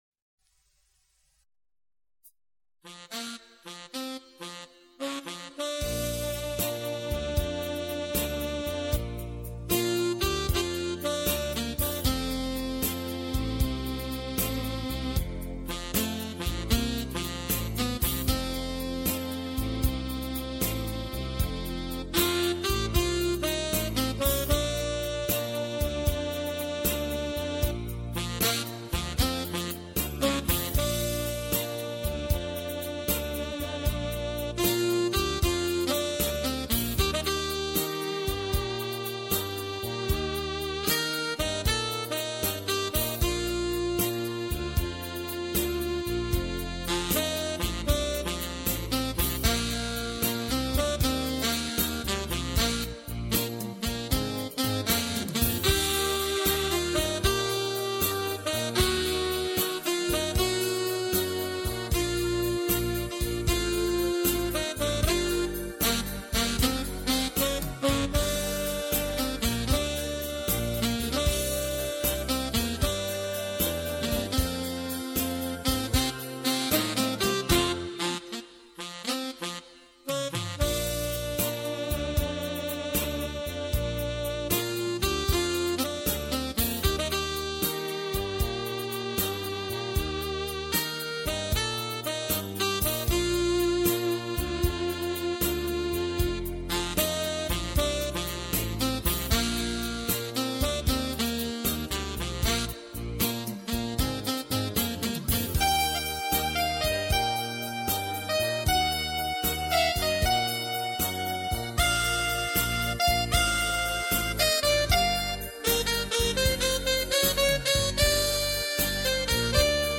Instrumentale Hits: